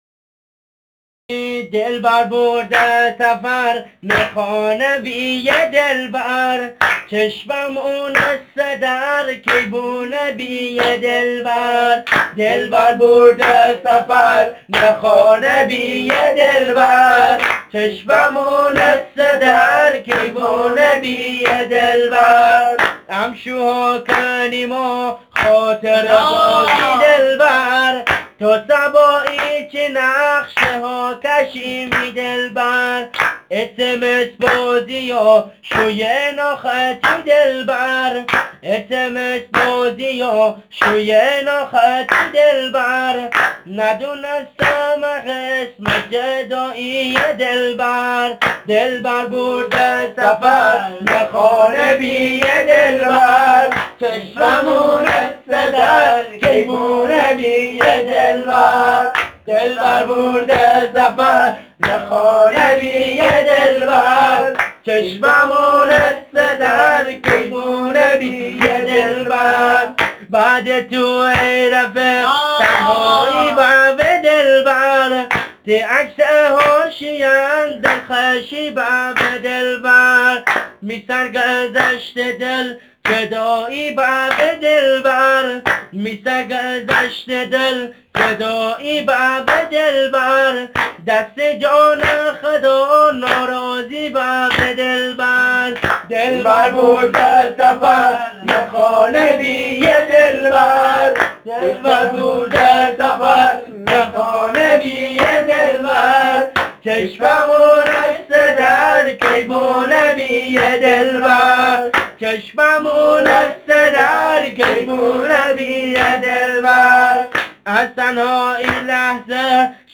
دانلود مازندرانی تک دست
آهنگ شاد